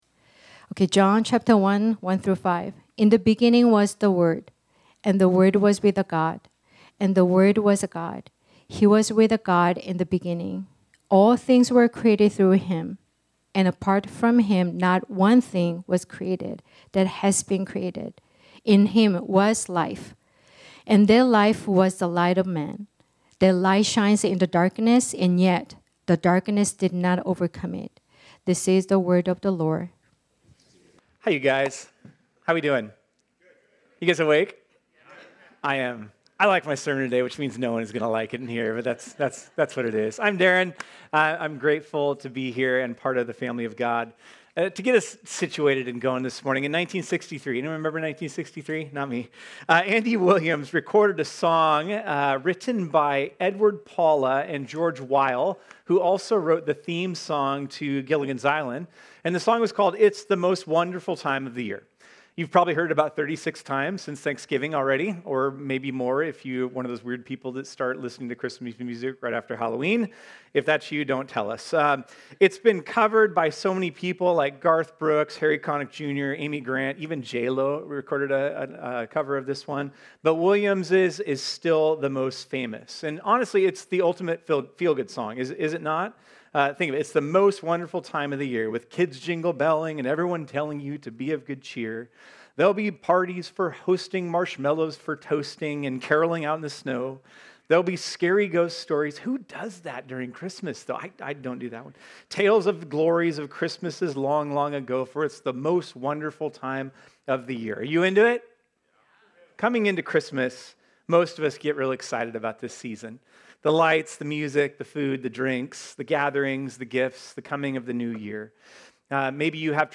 This sermon was originally preached on Sunday, December 7, 2025.